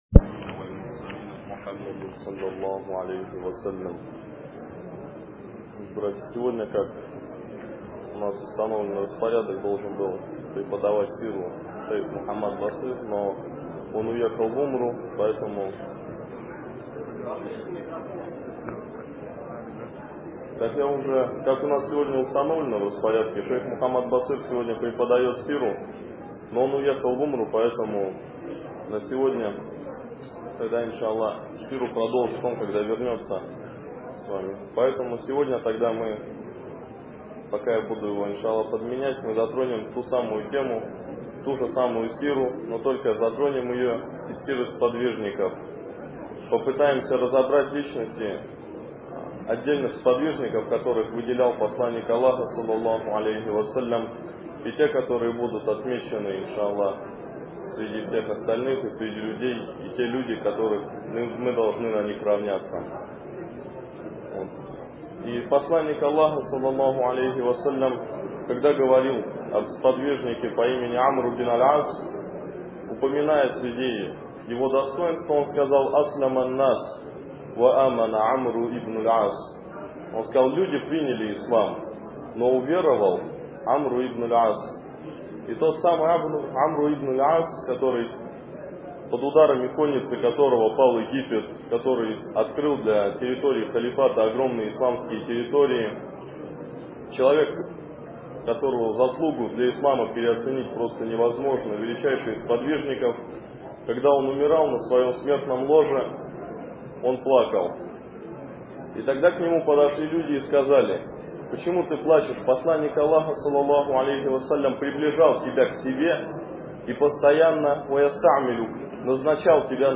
Лекции о праведных предках. Эта лекция о благородных сподвижниках АбдуЛЛахе ибн Масуде и Аммаре ибн Ясире, да будет доволен ими Аллах.